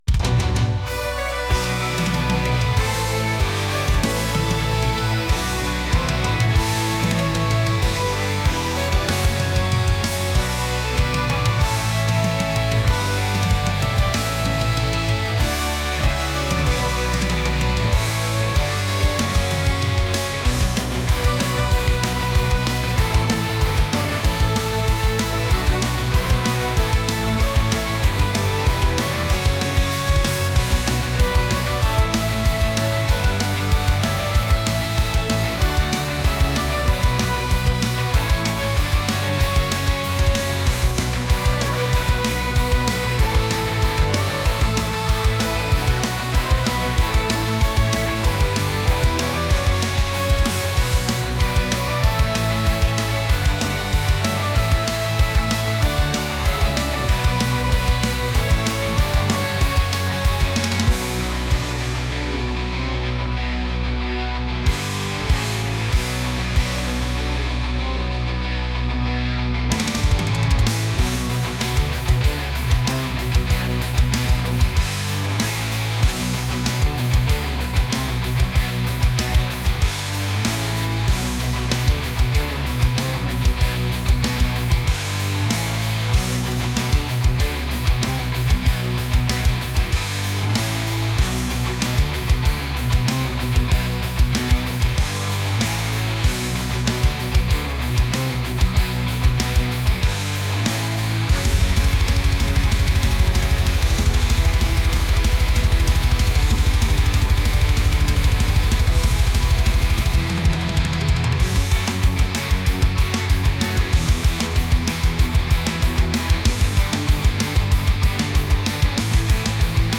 metal | heavy